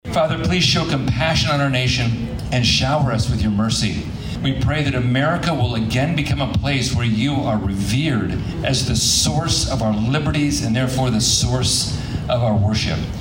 The local event for NDP was held at HeartMatters, located at 3401 Price Road in Bartlesville, on Thursday evening.